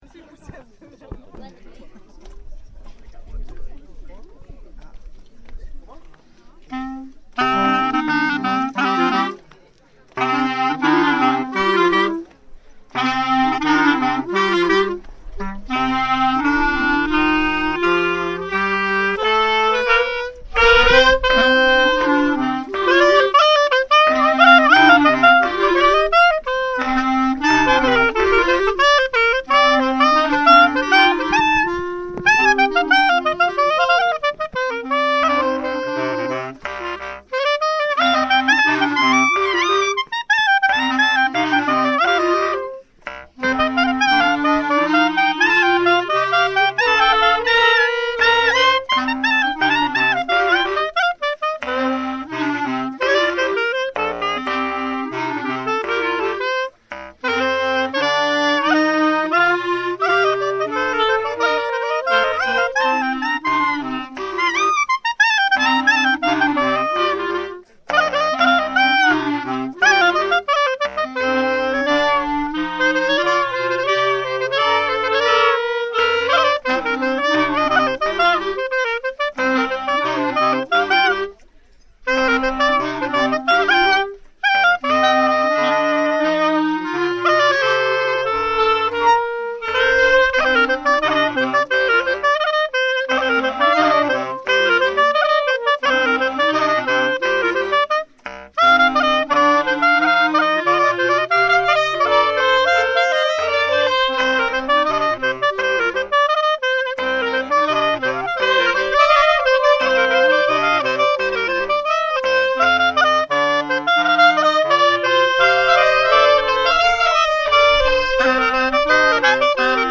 05_rond_sv_clarinette.mp3